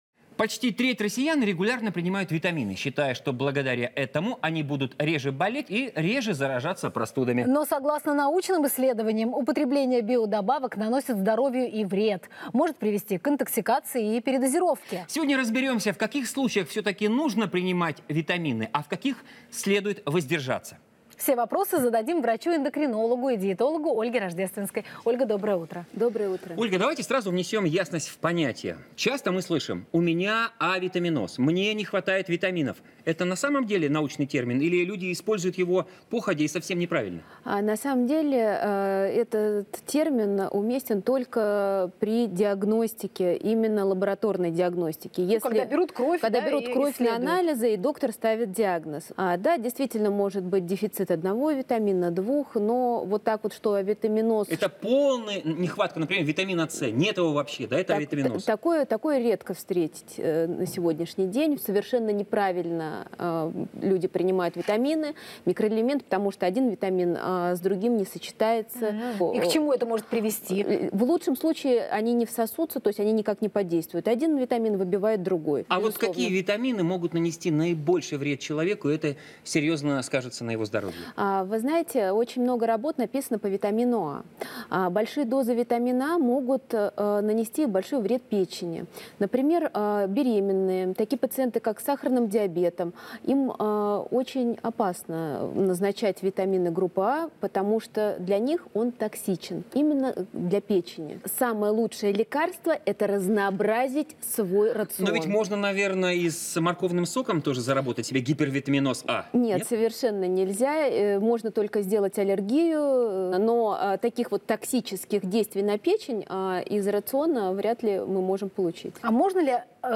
Источник: телеканал ТВЦ